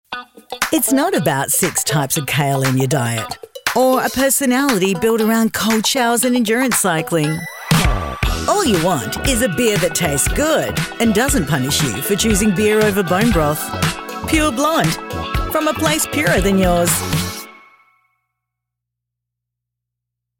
Female
English (Australian), English (Neutral - Mid Trans Atlantic)
Radio Commercials